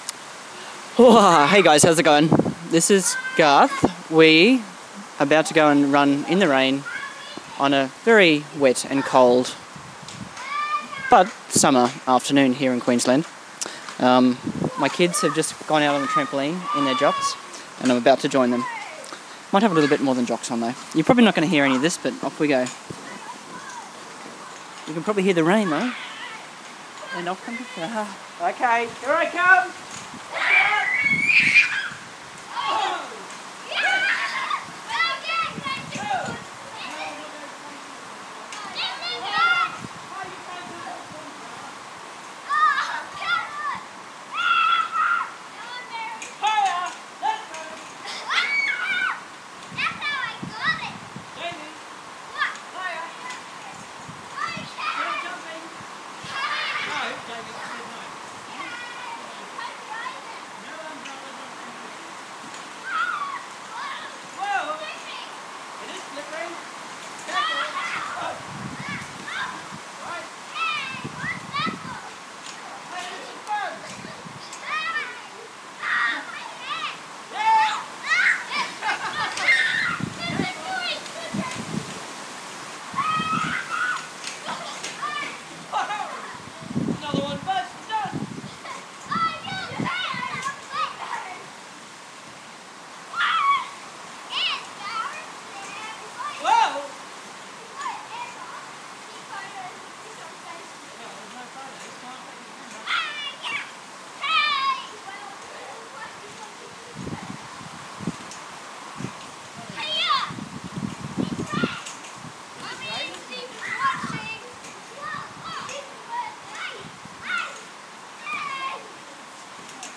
Trampoline in the rain